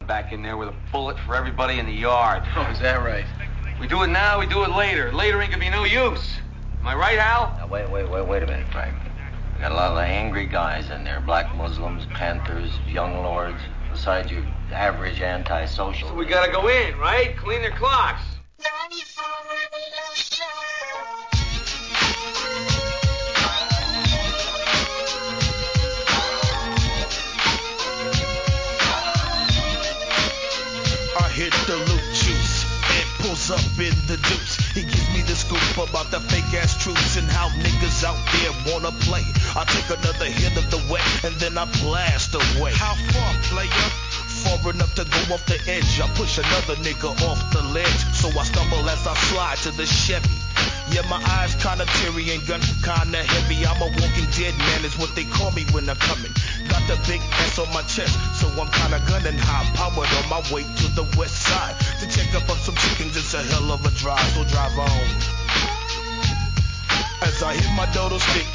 G-RAP/WEST COAST/SOUTH
GANGSTA RAPの名作CLASSICを4曲！！